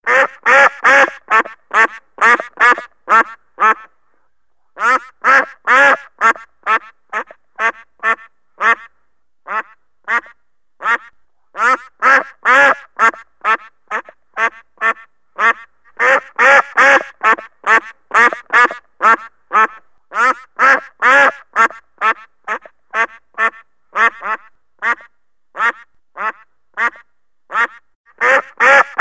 İndirmek ördek
Home » Indirmek Zil sesi ördek play stop mute max volume 00:00 -00:40 repeat × ördek ringtone download Download MP3 Close İndirmek ördek Kadar bekleyin 0 ördek MP3 File: İndirmek mp3 Indirildi, klicken Sie hier!